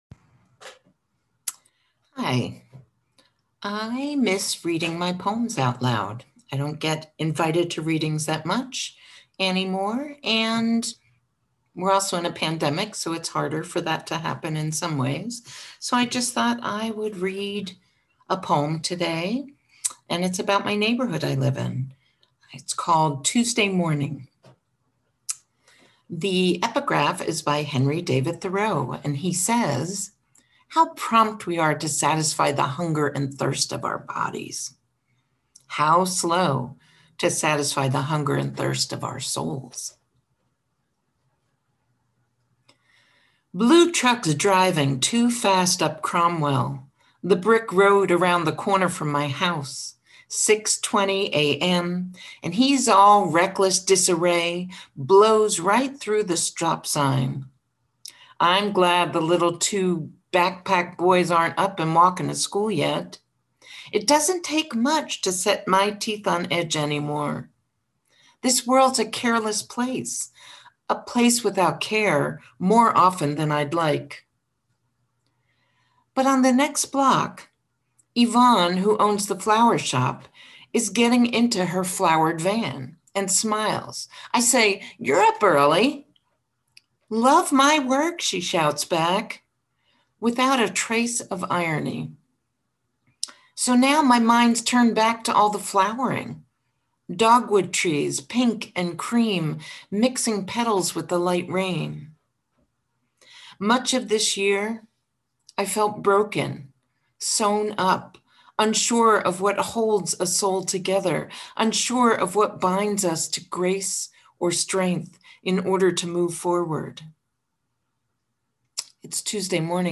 Do you have an MP3 of you reading a poem? Send it along.
Reading-Tuesday-Morning.mp3